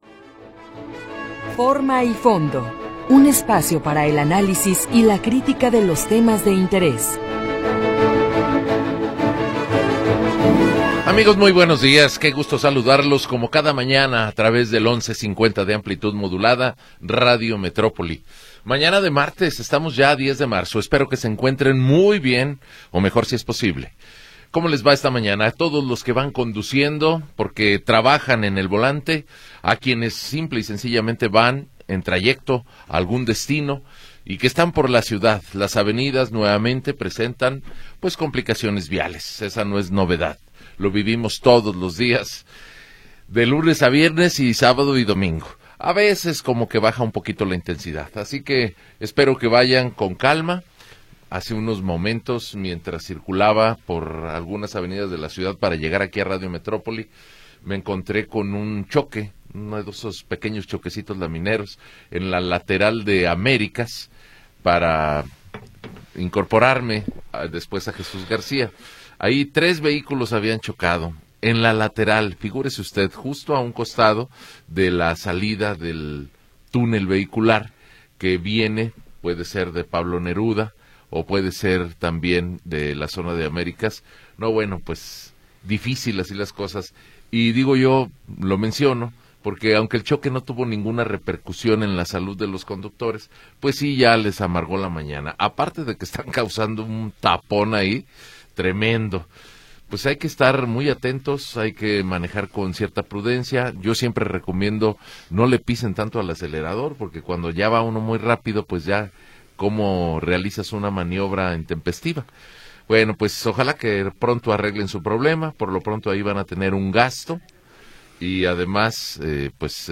Programa transmitido el 10 de Marzo de 2026.